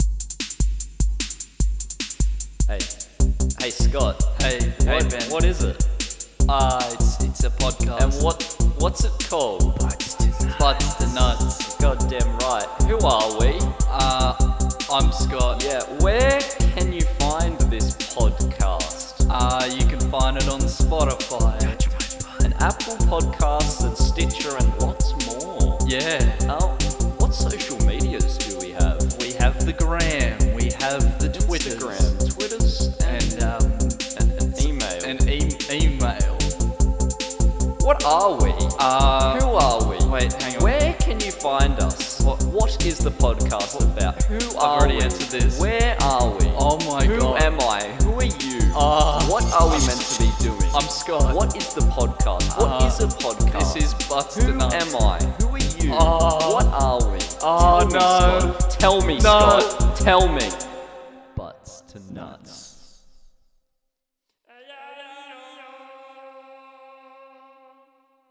A brand spanking new podcast where two very serious dudes review movies from all genres, all eras of cinema and most countries (we'd be lying if we said all countries seeing as there is a lot of them).  Podcast offically launches early 2022!